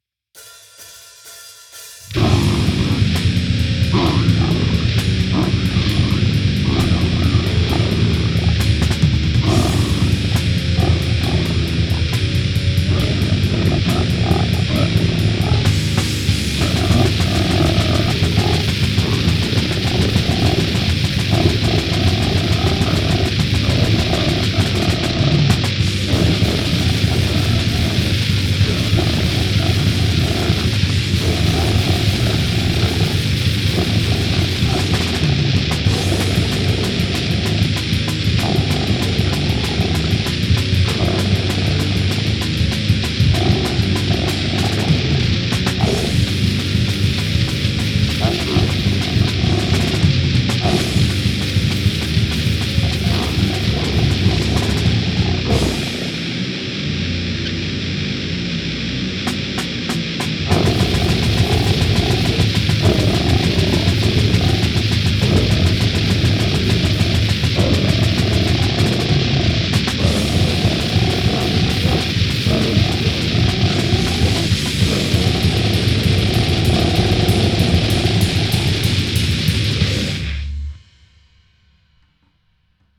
pure brutal death sounds suitable for christmas